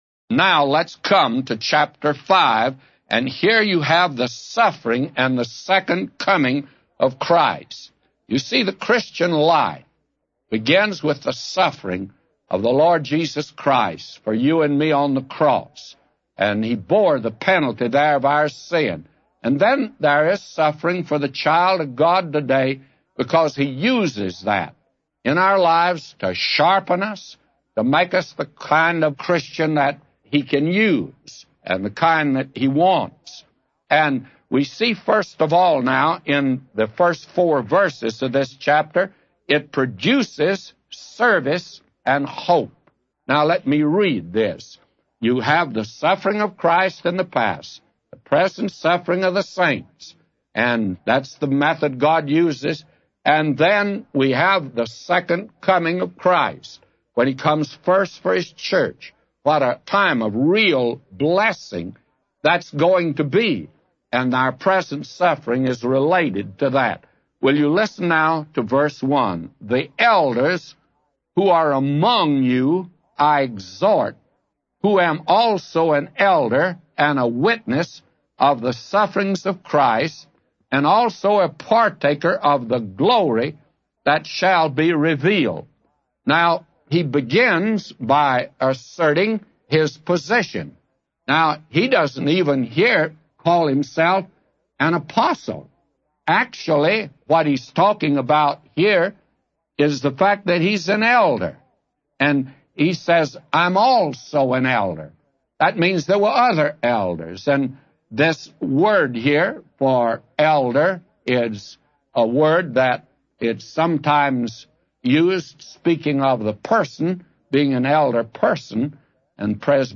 A Commentary By J Vernon MCgee For 1 Peter 5:1-999